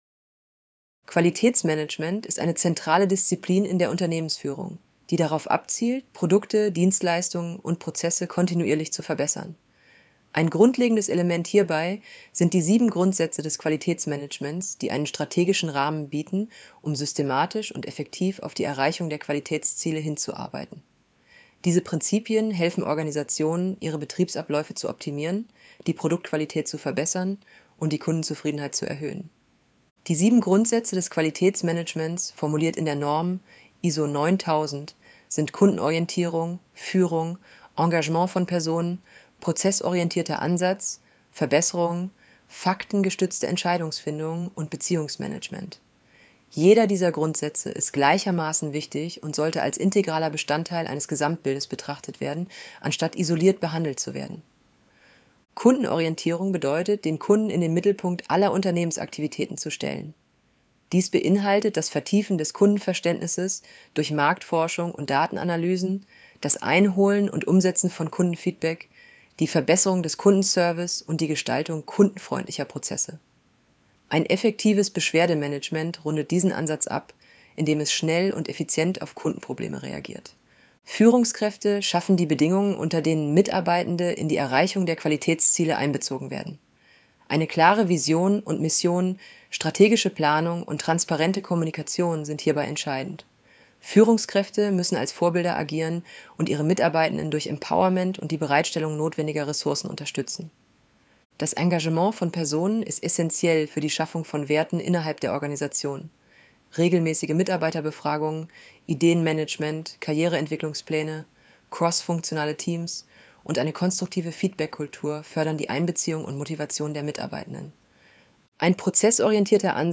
Hinweis: Diese Vorlesefunktion verwendet eine synthetisch erzeugte Stimme aus einem KI-System.Die Stimme ist keine Aufnahme einer realen Person.